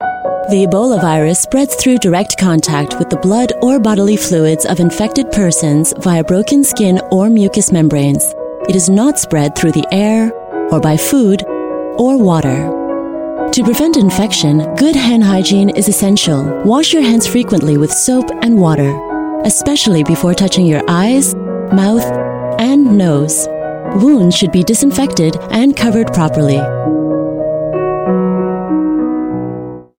Voice Samples: HK Government Ebola Ammouncement
female